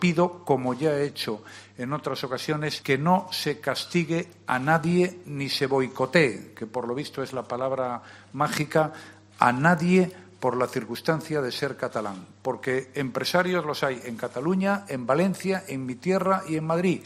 En una breve alocución ante la prensa, el presidente del Gobierno ha pedido "que no se castigue a nadie ni se boicotee a nadie por la circunstancia de ser catalán" y ha recalcado que Freixenet y las demás compañías catalanas merecen "el mismo trato" que cualquier otro empresario español.